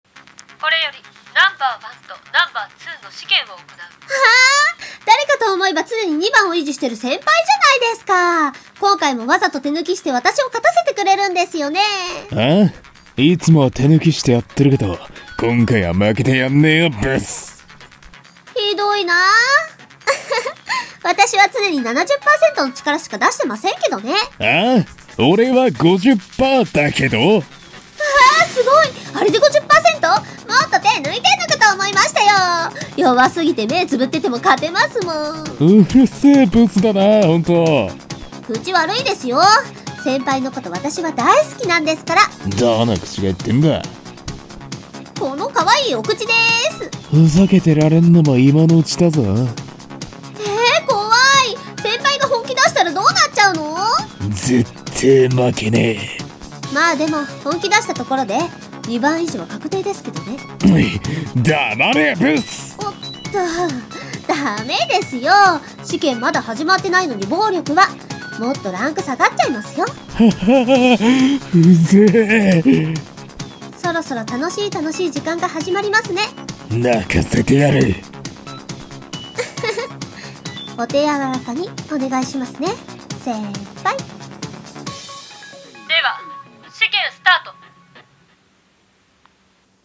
【二人声劇】No.1とNo.2